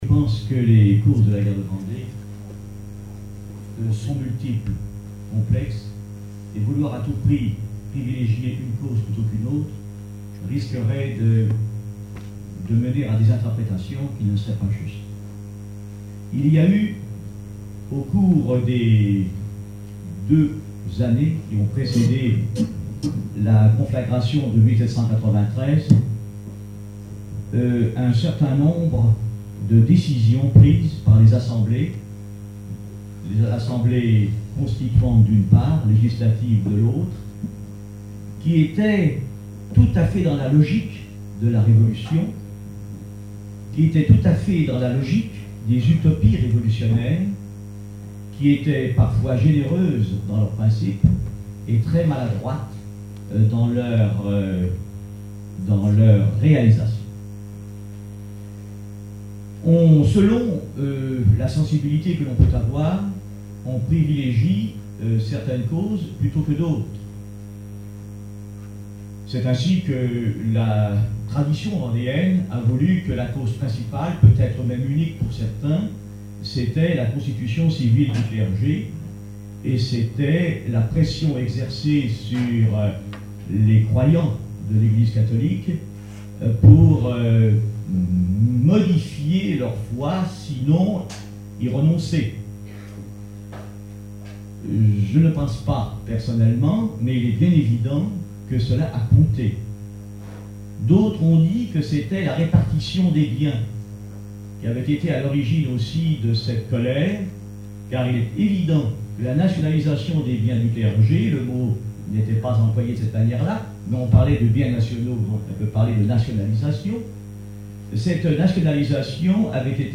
congrès, colloque, séminaire, conférence
Conférence de la Société des écrivains de Vendée